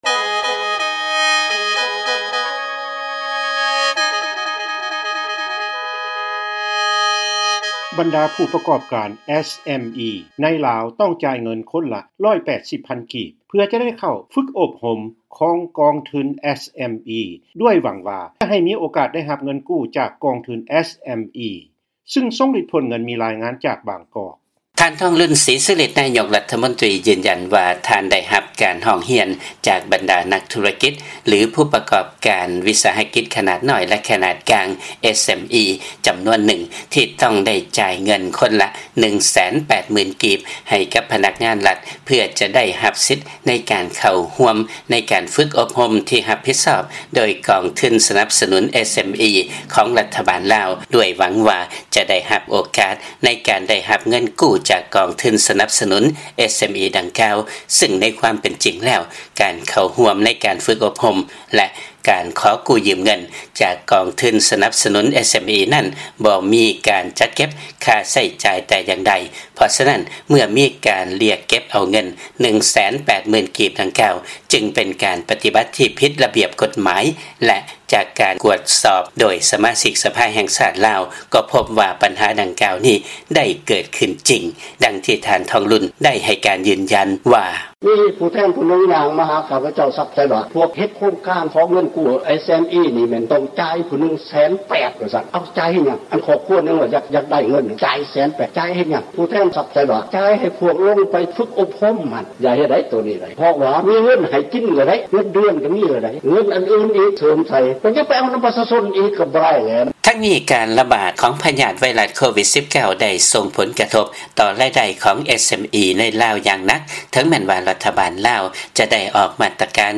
ຟັງລາຍງານ ບັນດາຜູ້ປະກອບການ SMEs ໃນລາວ ຕ້ອງຈ່າຍເງິນຄົນລະ 180,000 ກີບ ເພື່ອຈະໄດ້ເຂົ້າຮ່ວມການຝຶກອົບຮົມ ຂອງກອງທຶນ SME